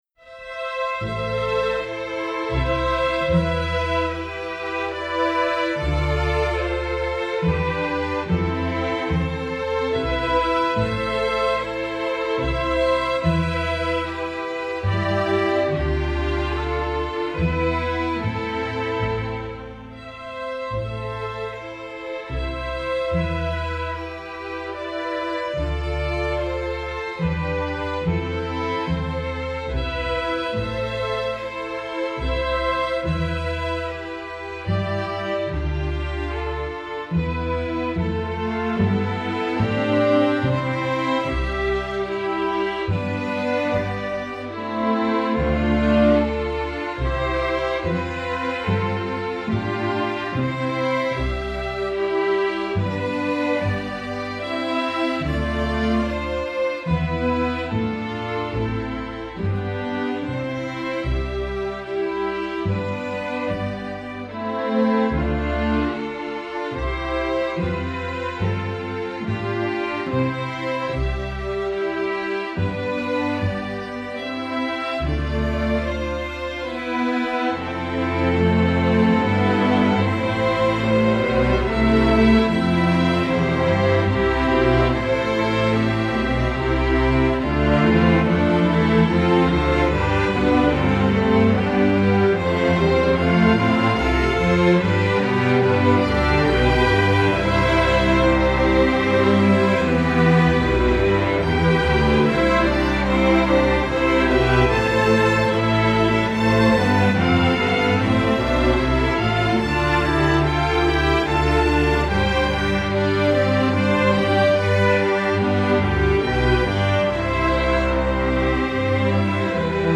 Instrumentation: string orchestra
instructional